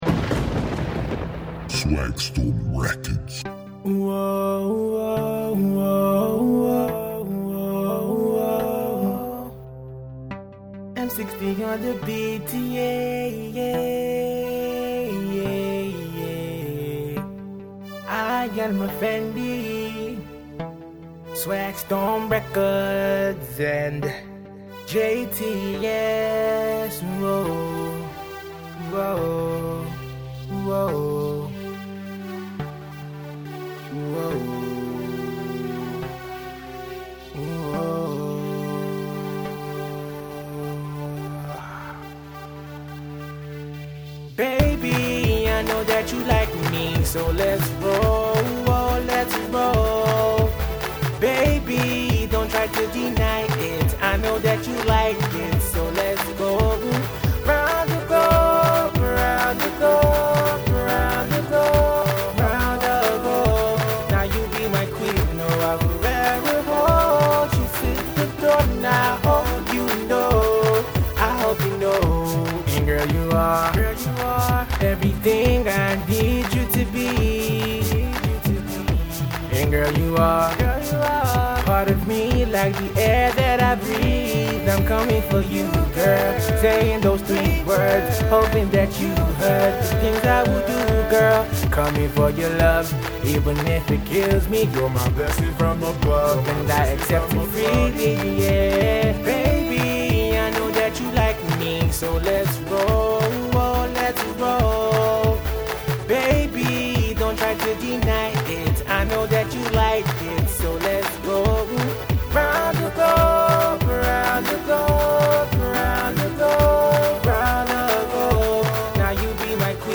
the budding singer croons one for the ladies.